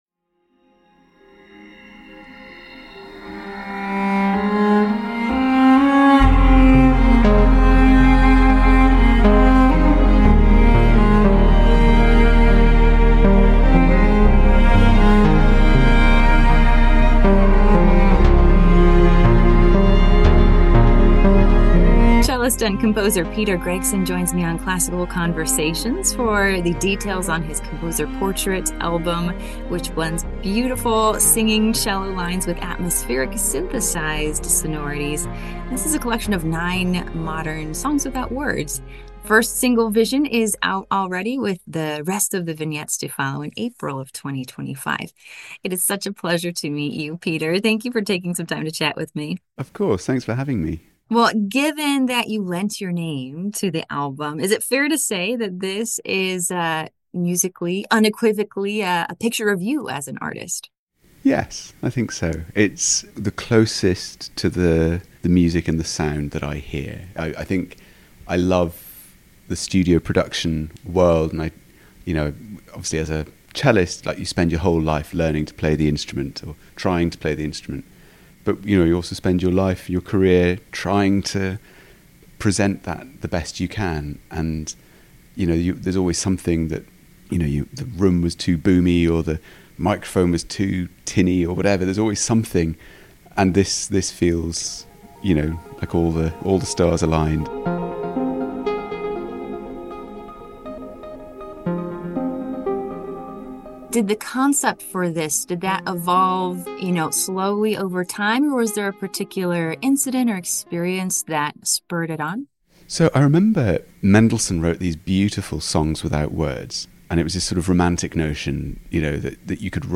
Classical-Conversation-with-Peter-Gregson-Export_0.mp3